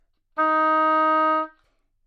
双簧管单音 " 双簧管 D4
描述：在巴塞罗那Universitat Pompeu Fabra音乐技术集团的goodsounds.org项目的背景下录制。
Tag: 好声音 单注 多重采样 纽曼-U87 Dsharp4 双簧管